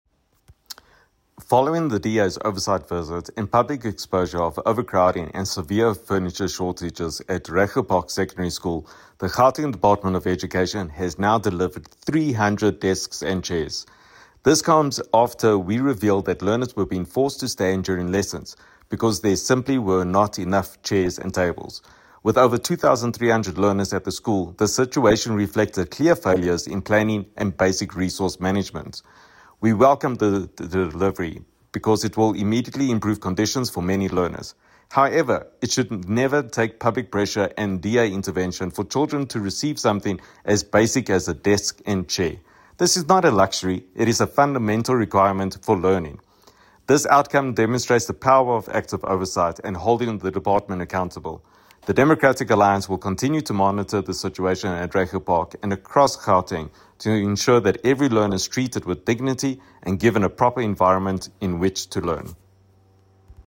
soundbite by Sergio Isa Dos Santos MPL.